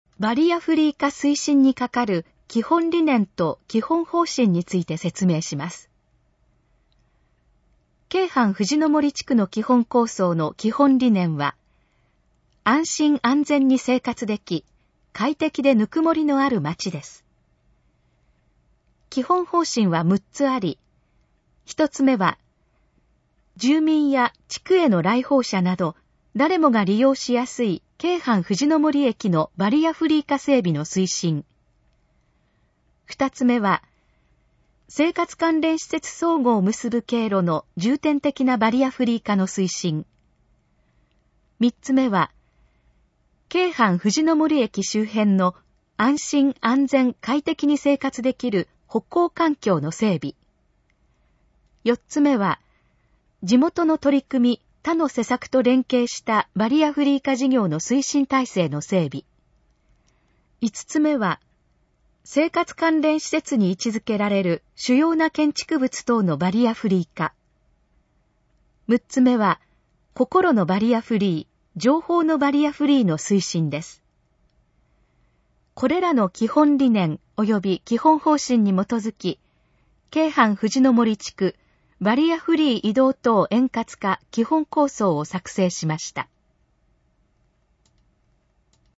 このページの要約を音声で読み上げます。
ナレーション再生 約348KB